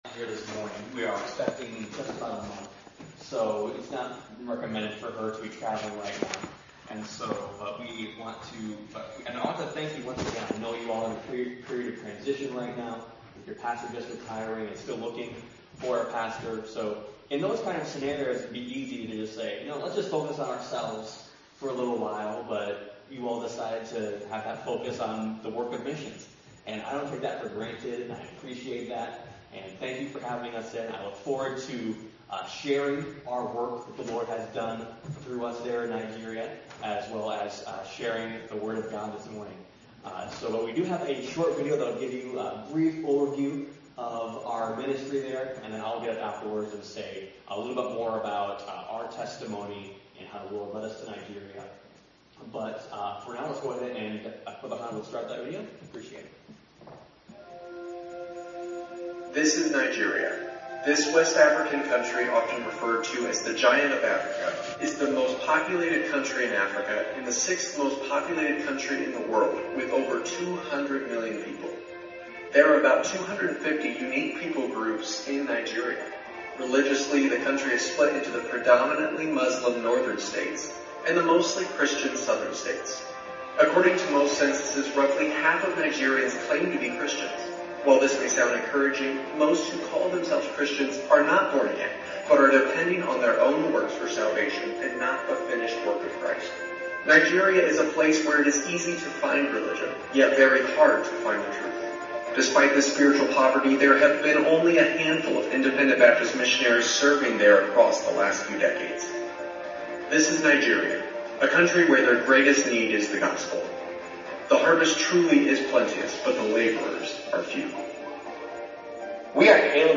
Passage: Acts 27 Service Type: Sunday Morning